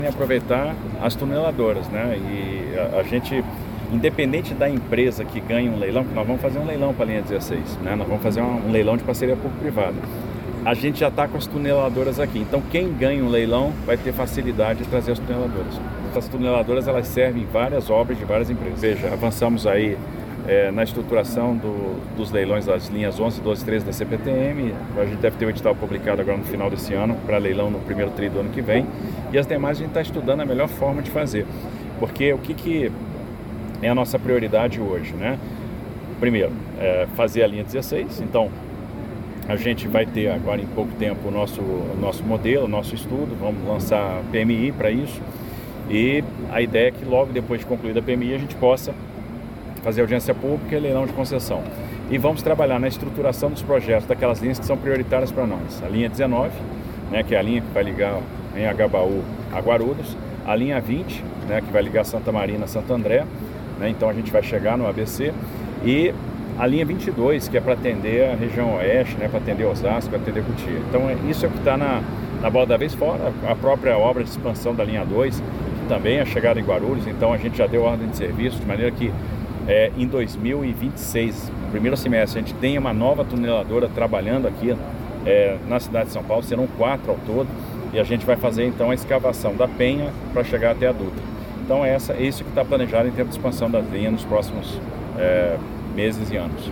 Em vistoria ao primeiro trem do monotrilho da linha 17-Ouro nesta terça-feira, 1º de outubro de 2024, o governador de São Paulo, Tarcísio de Freitas, informou que as tuneladoras (tatuzões) usadas pela Acciona para implantar a linha 6-Laranja de metrô (São Joaquim/Vila Brasilândia) devem ser aproveitadas para a construção da linha 16-Violeta, que ligará a região de Oscar Freire, na zona Oeste da capital paulista, até Abel Ferreira, na zona Leste, com planos de posterior expansão para a Cidade Tiradentes, no extremo leste.